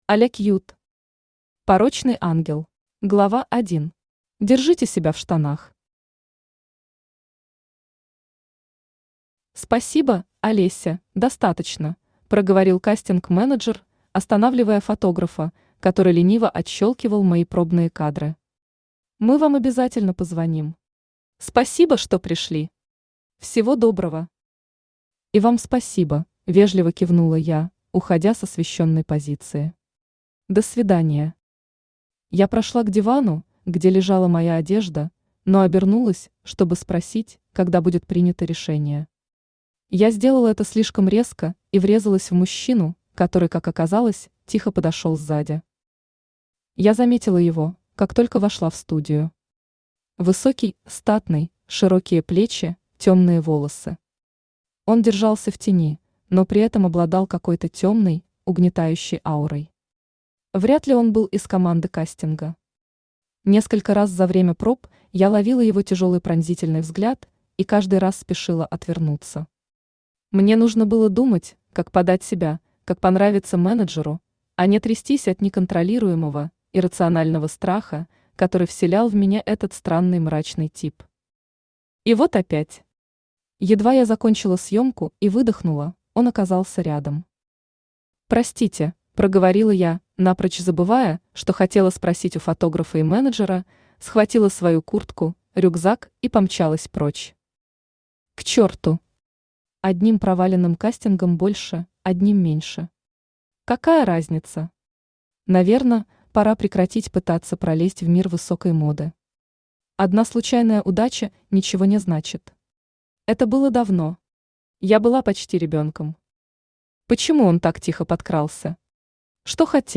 Aудиокнига Порочный ангел Автор Аля Кьют Читает аудиокнигу Авточтец ЛитРес.